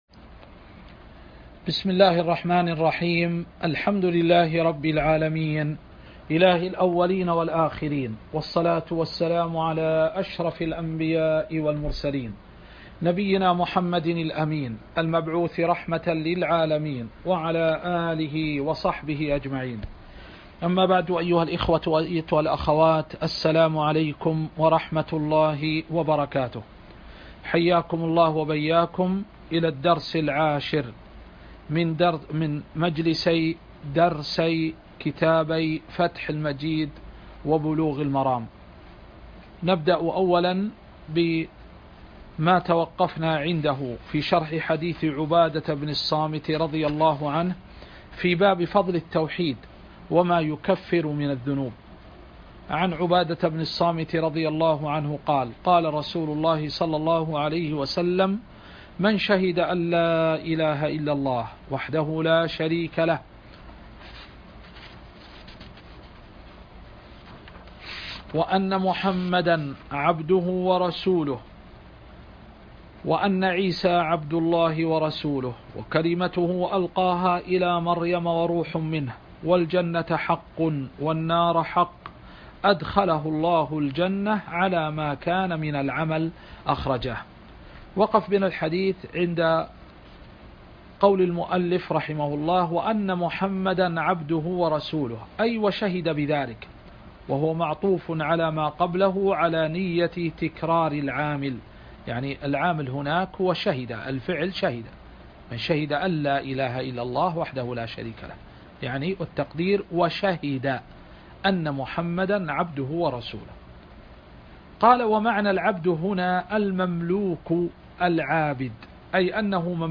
الدرس (10) شرح كتاب فتح المجيد وكتاب بلوغ المرام